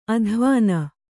♪ adhvāna